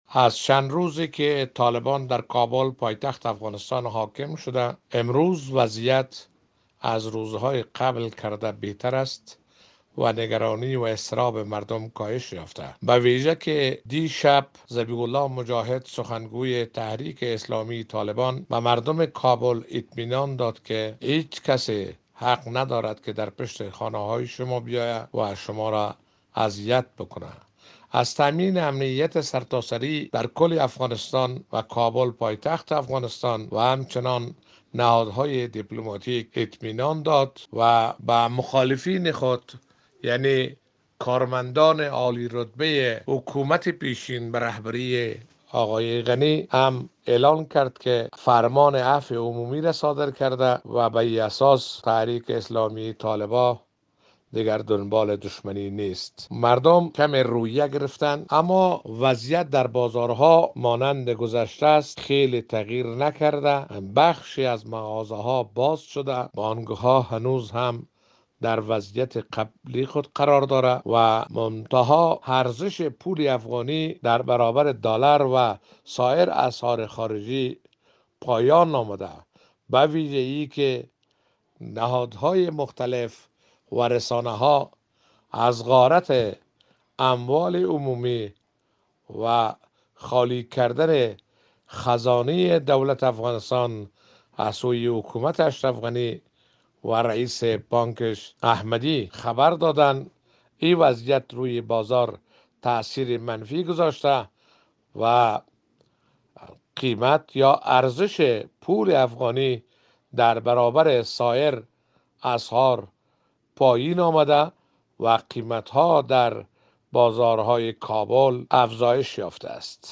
گزارش از آخرین وضعیت کابل
خبرنگار رادیو دری از آخرین وضعیت کابل گزارش میدهد.